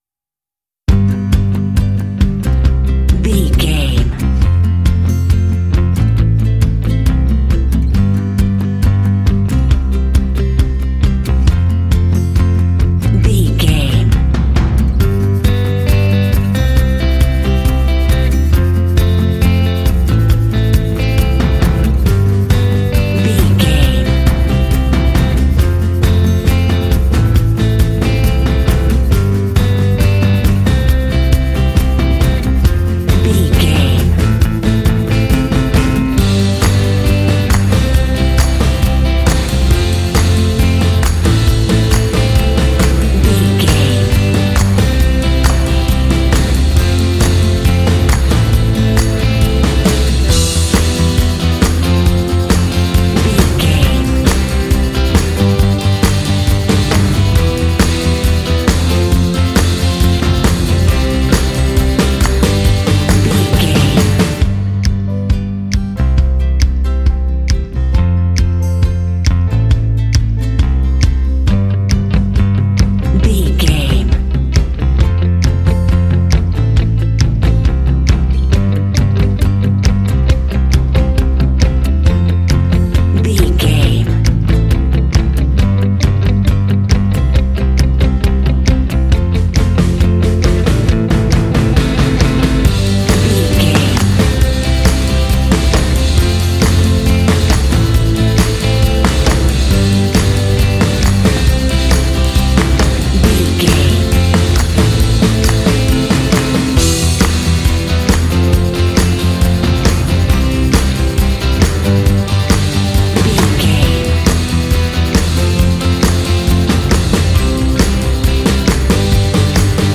Fun and cheerful indie track with bells and “hey” shots.
Uplifting
Ionian/Major
playful
acoustic guitar
electric guitar
bass guitar
drums
piano
alternative rock